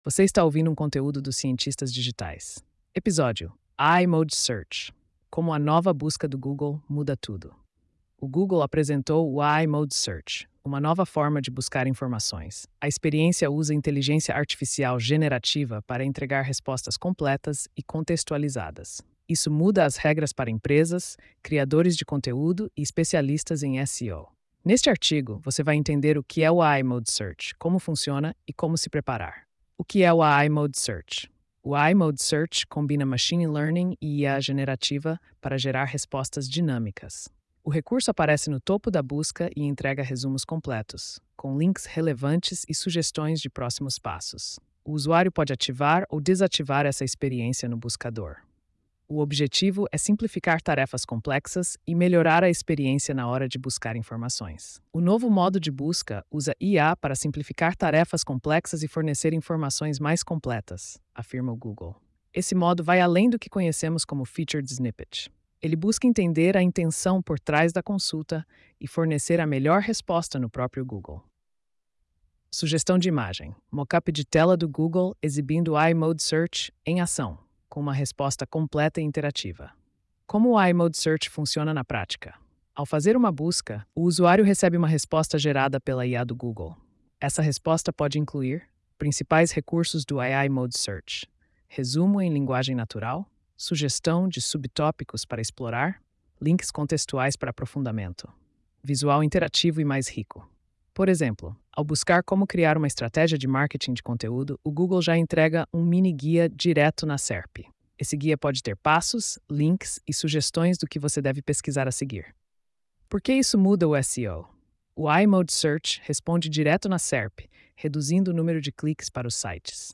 post-3225-tts.mp3